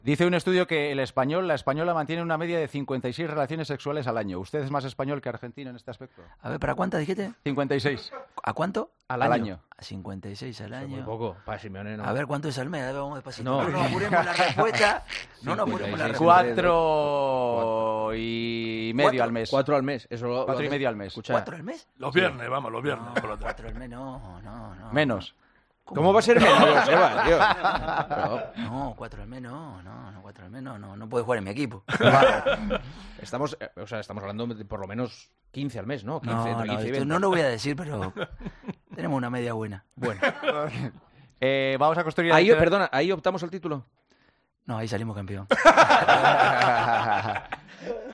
acabó en esta reflexión del técnico que sacó las carcajadas de todos.